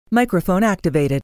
Microphone-activated-teamspeak.mp3